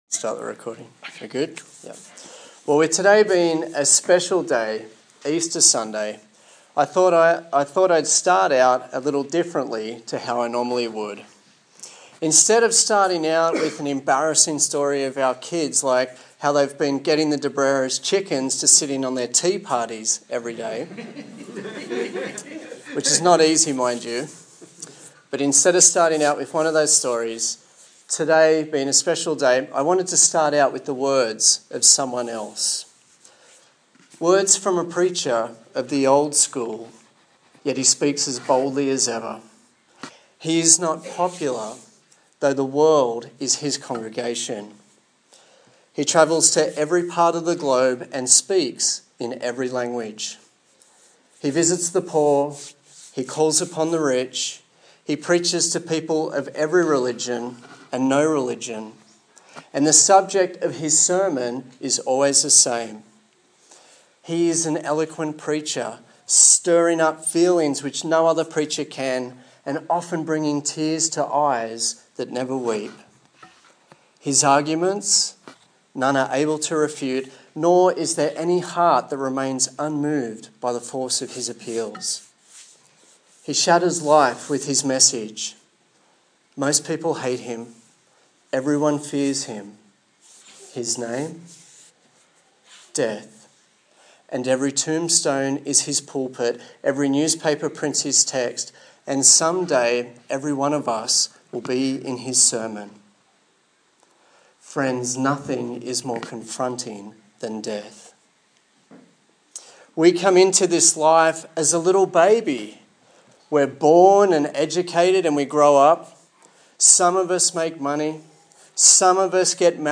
Passage: 1 Corinthians 15:20-58 Service Type: Sunday Morning